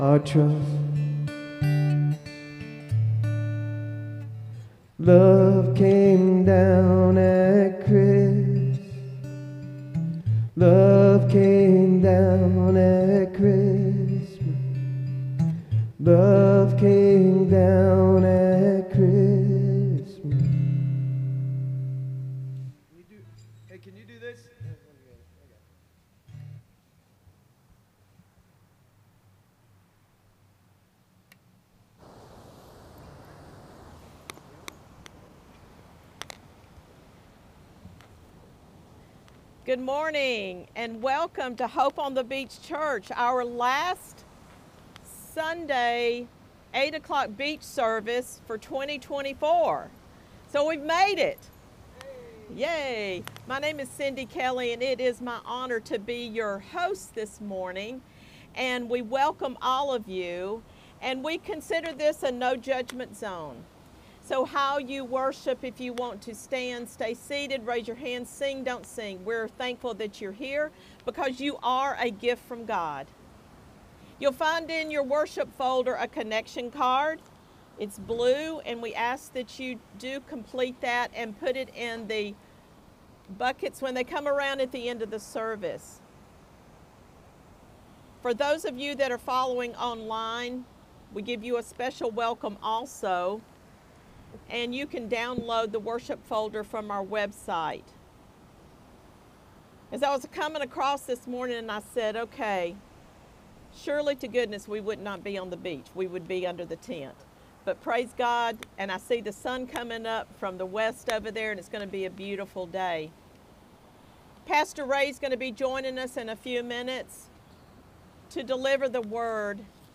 Download Download Reference Matthew 1:18-25 NLT, 28:20 NIV Sermon Notes Click Here for Notes 241229.pdf SERMON DESCRIPTION We conclude the series by focusing on the name "Immanuel," meaning "God with us."